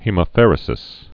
(hēmə-fĕrə-sĭs, hĕmə-)